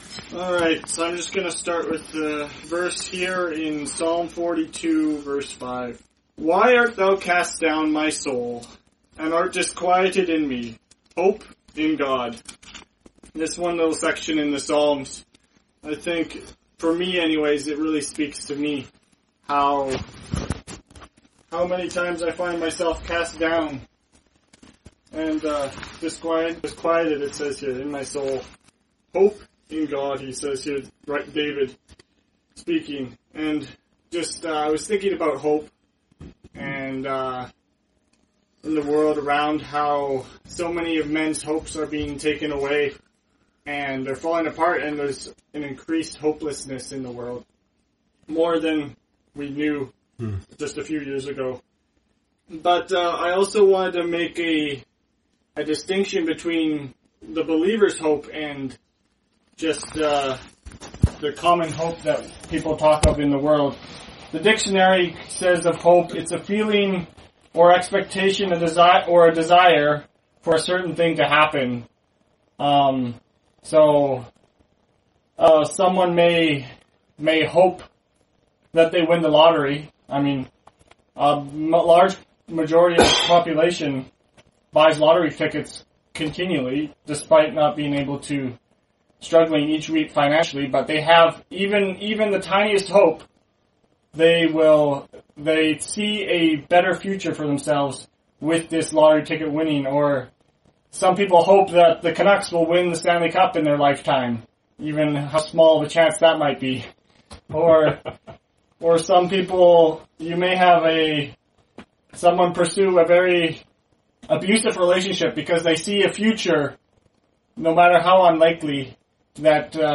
The Bible says the only way to find true living hope is in the person of the Lord Jesus Christ. In this Gospel preaching, you will hear of the hope Jesus can give you throughout an ever-changing world.